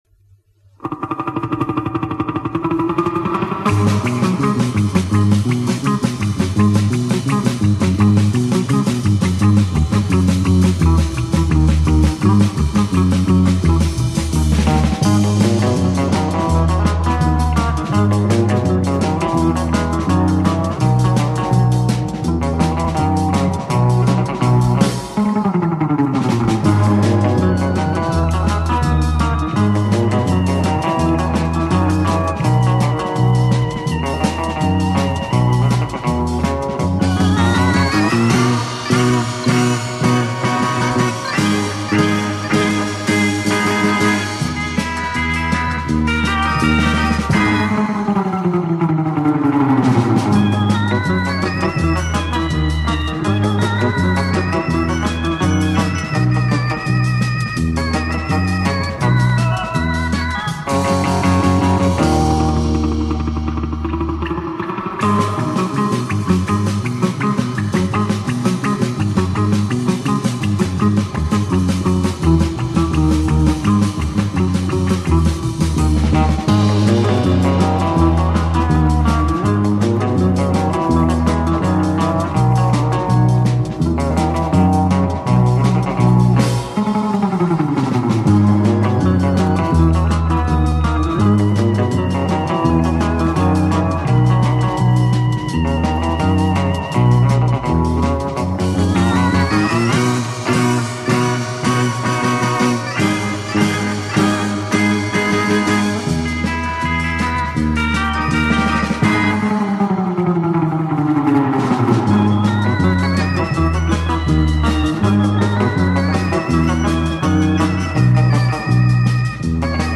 Формат: Vinil, EP, Mono, 33 ⅓, Album
Стиль: Pop Rock, Schlager